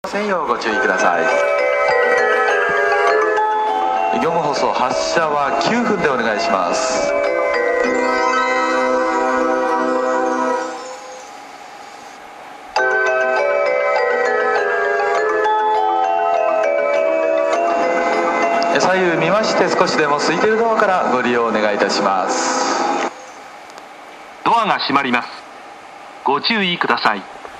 スピーカーはユニペックス製で音質はとてもいいと思います。
１番線JM：武蔵野線